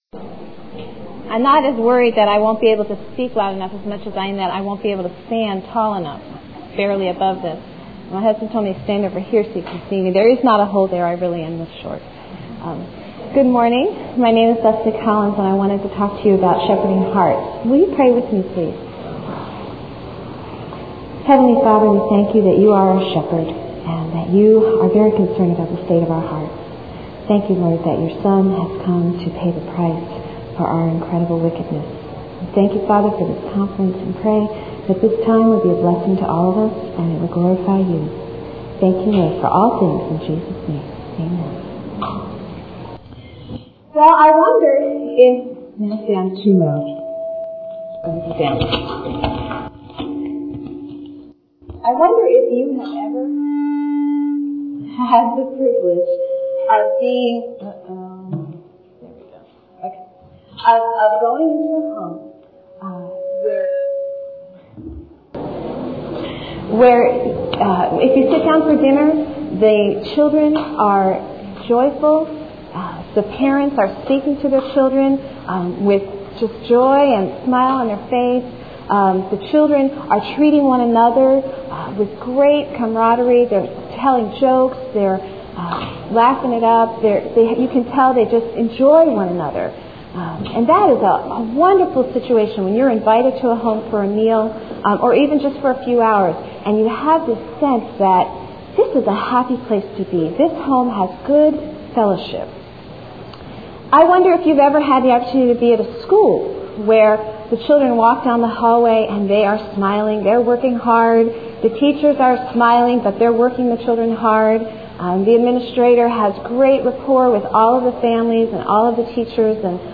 2000 Workshop Talk | 0:39:44 | All Grade Levels, Virtue, Character, Discipline